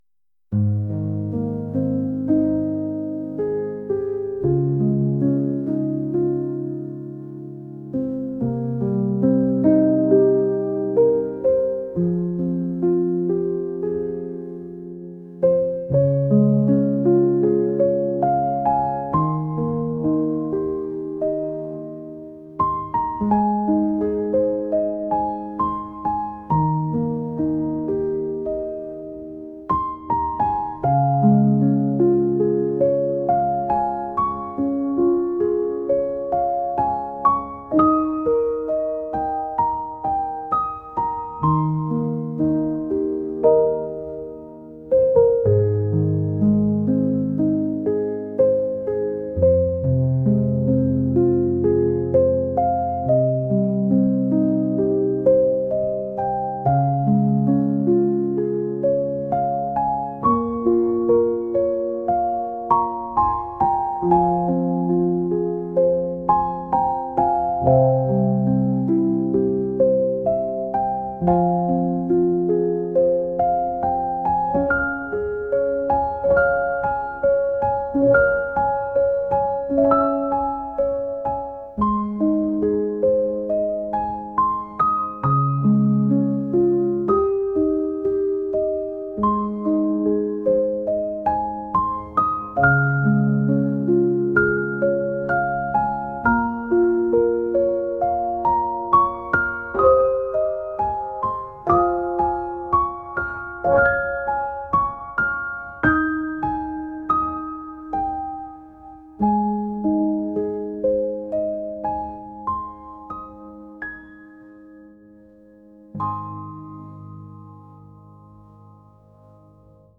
acoustic | ambient | lofi & chill beats